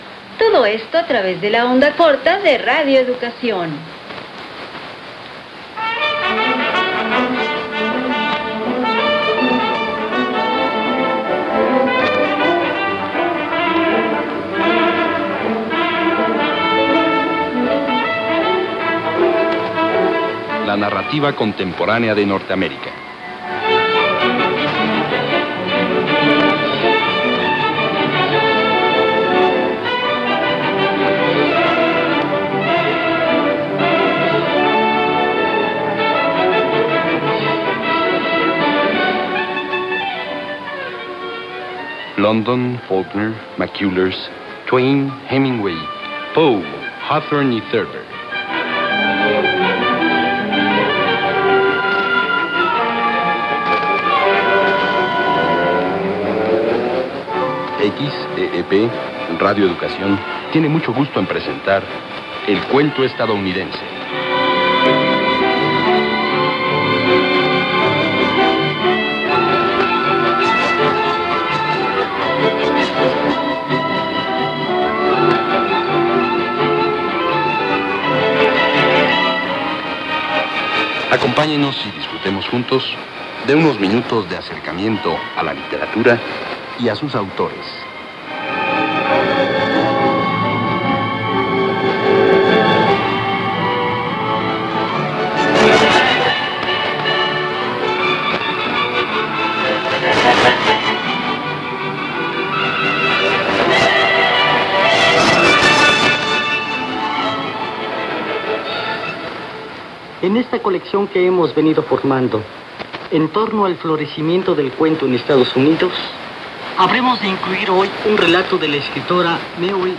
Programa en torno al florecimiento del cuento de Estados Unidos, transmitido por Radio Educación en Onda Corta.
Frecuencia: 6185 khz.
Receptor: Kenwood R-600 Antena: Hilo largo 20 metros a una altura de 6 metros con conexión a tierra.